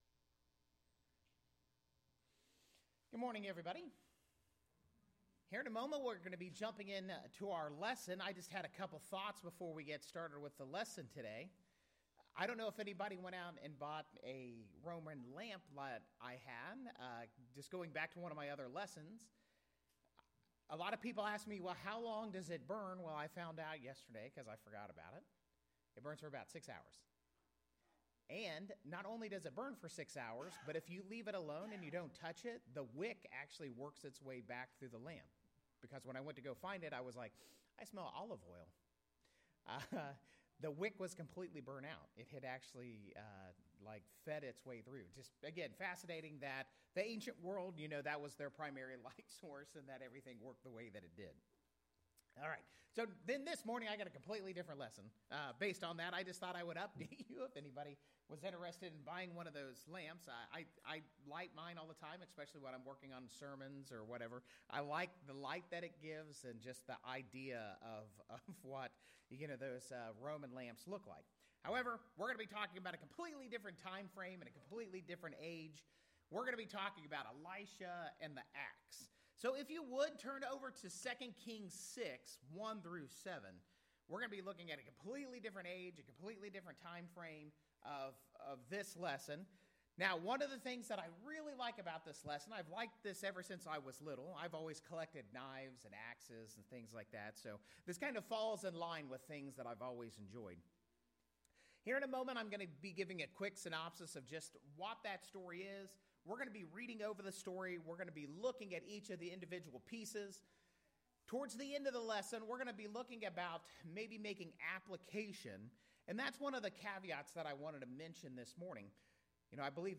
The sermon’s message was to highlight spiritual vigilance, renewal, and responsibility: